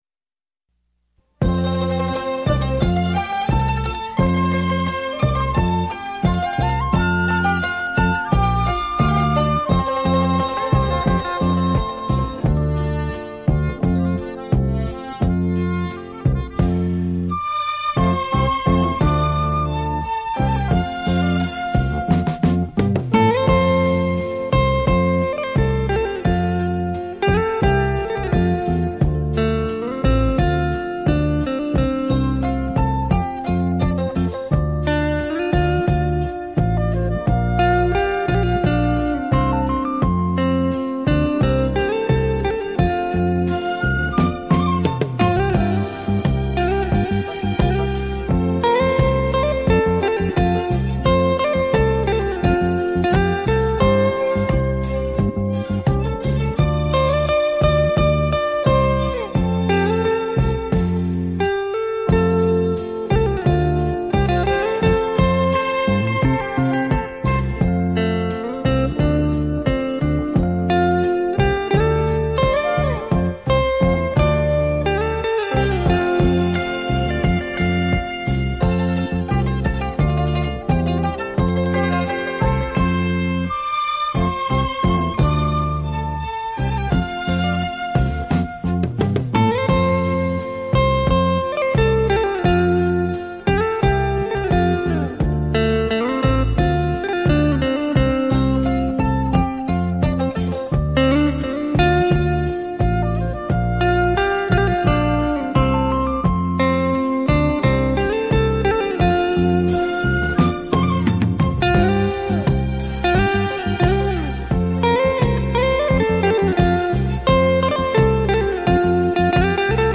名曲的旋律·抒情的回忆
畅销东洋演歌名曲演奏